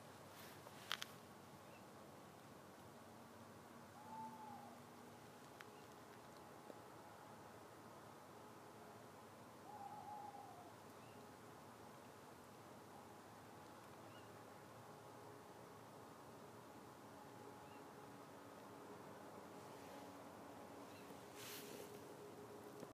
Spesielt rundt solnedgang har det vore mykje uling i det siste.
Samtidig høyrte eg noko pipelyd mellom ulinga med retning fra naboen si pipe. I dag prøvde eg ta eit lydopptak, men på litt avstand. I lydklippet høyrer du svakt pipelyden.
ugle.m4a